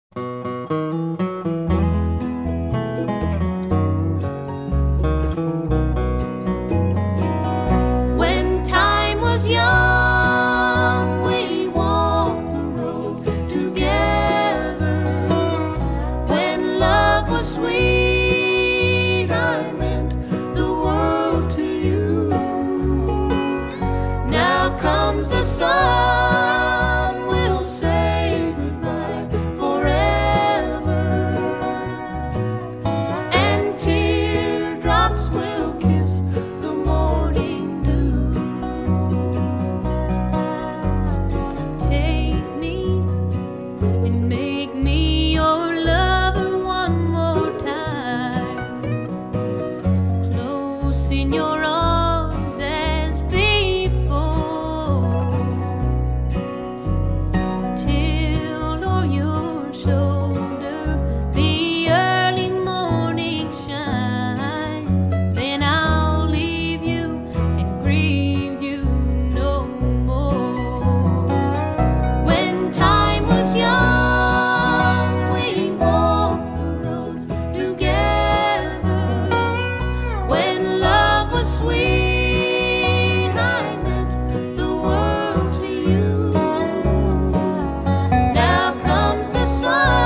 ★ 藍草音樂天后早期代表作，雙白金暢銷專輯！
★ 全音音樂網站五顆星無條件推薦，《滾石》雜誌四星高評，歌曲首首動聽，錄音鮮活絲滑！